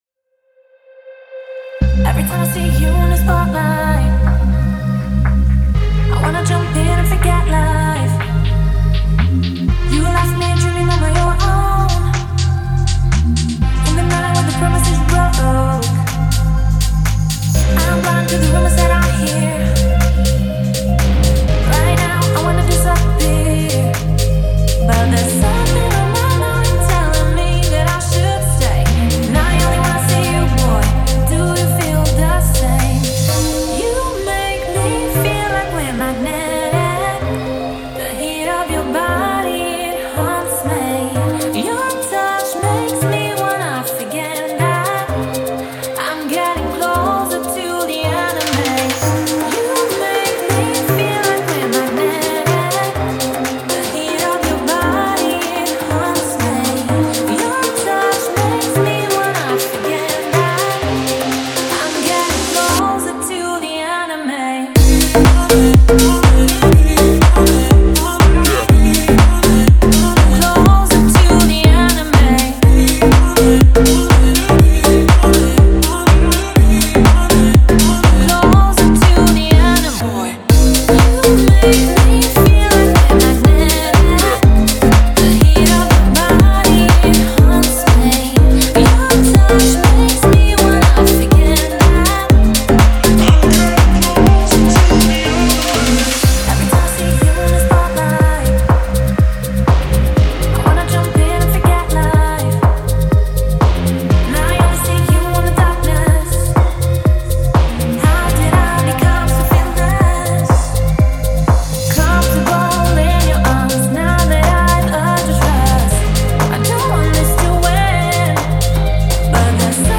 это завораживающий трек в жанре deep house